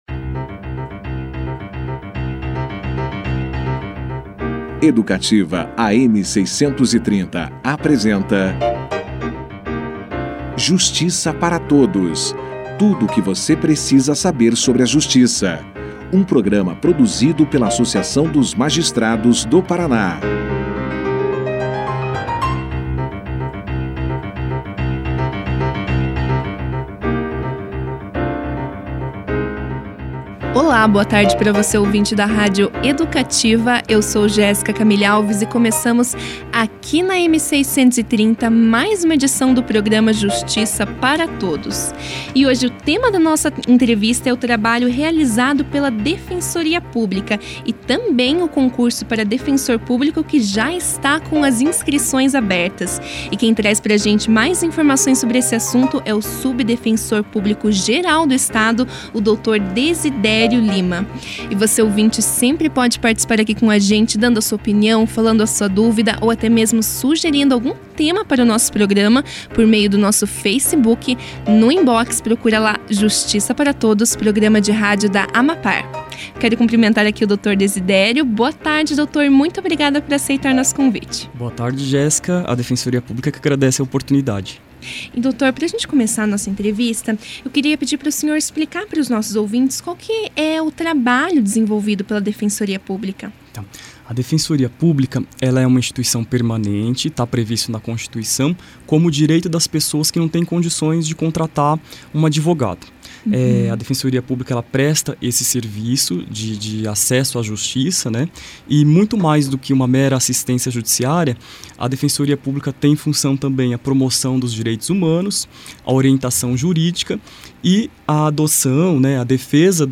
O programa Justiça para Todos recebeu nos estúdios da rádio Educativa, AM 630, o defensor público Dezidério Lima que falou mais ao ouvinte sobre o trabalho realizado na Defensoria Pública.
Confira a entrevista na íntegra com o subdefensor público geral do Estado, Dezidério Lima